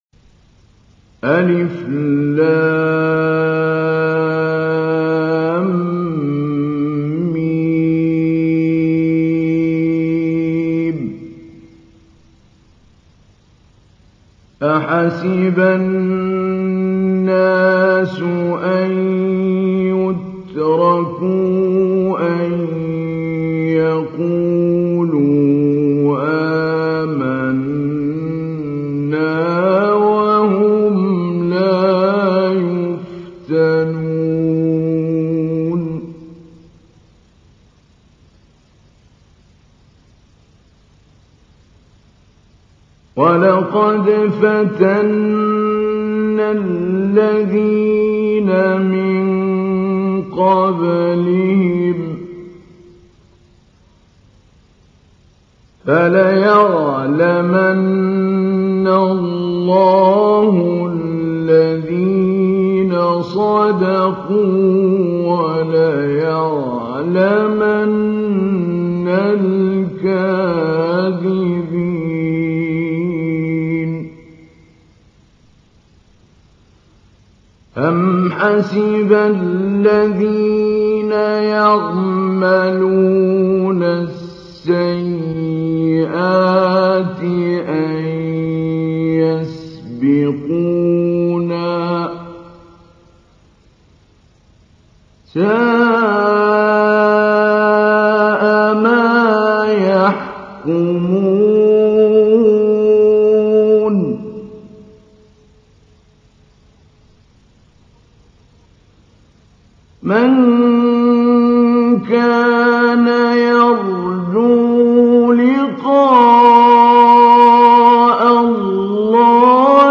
تحميل : 29. سورة العنكبوت / القارئ محمود علي البنا / القرآن الكريم / موقع يا حسين